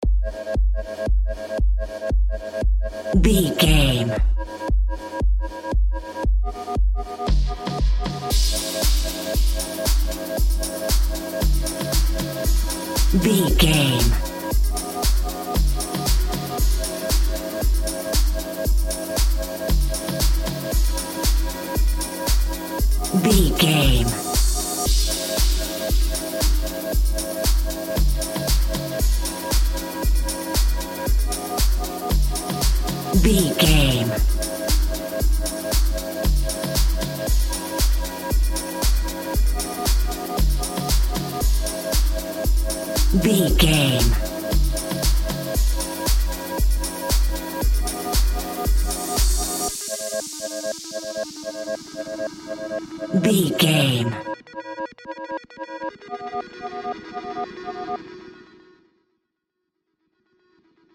Aeolian/Minor
ethereal
dreamy
cheerful/happy
groovy
synthesiser
drum machine
house
electro dance
synth leads
synth bass
upbeat